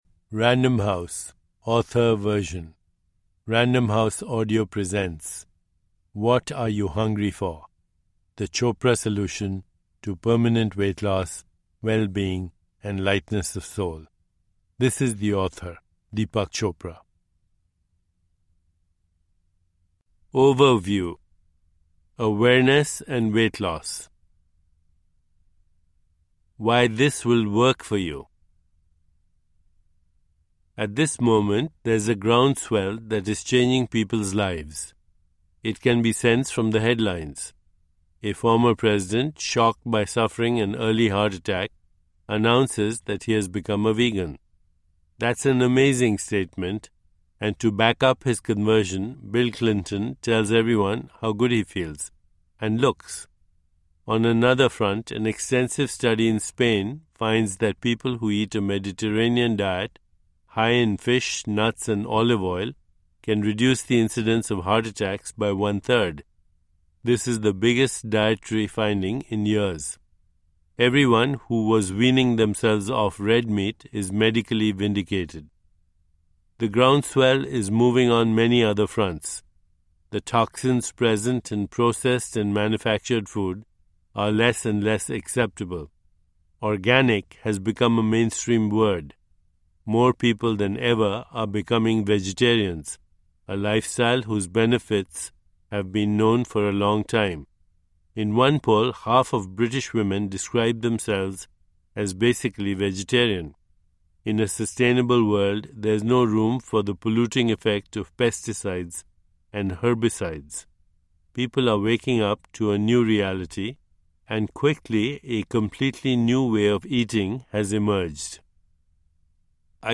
What Are You Hungry For? – Ljudbok
Uppläsare: MD Chopra, Deepak